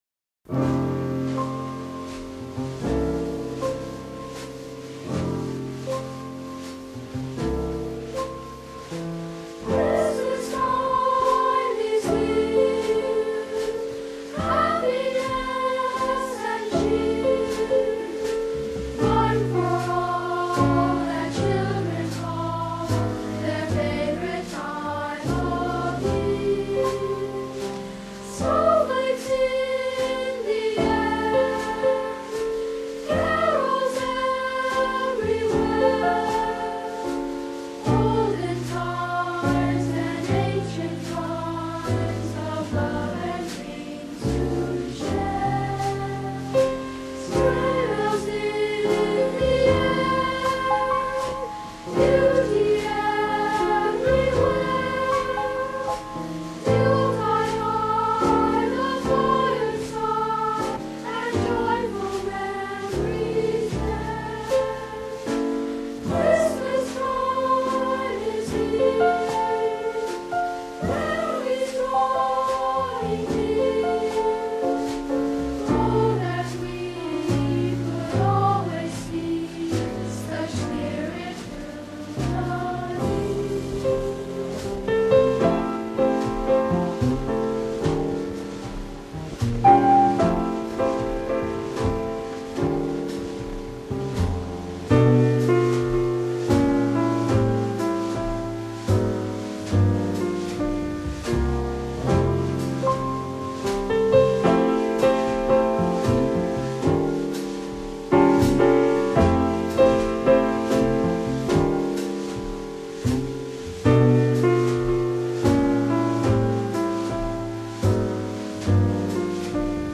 jazz xmas mess.mp3